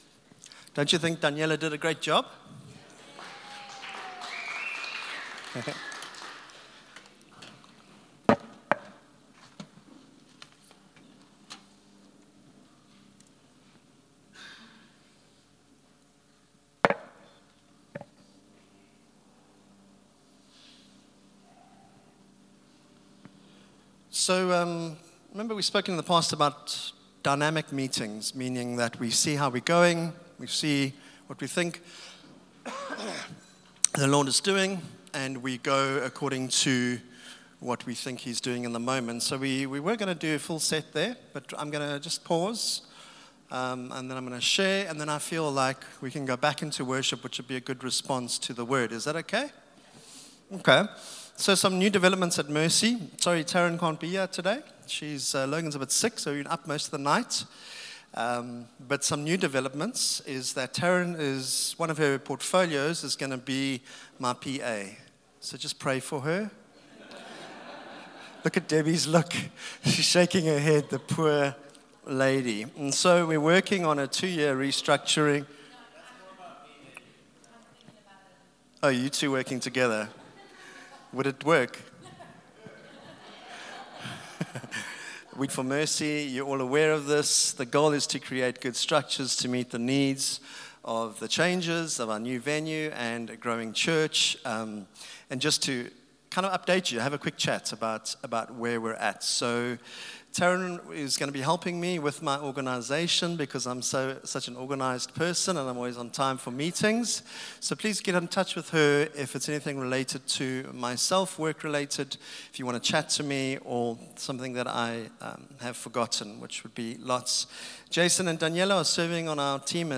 Sunday Service – 2 Feb
Sermons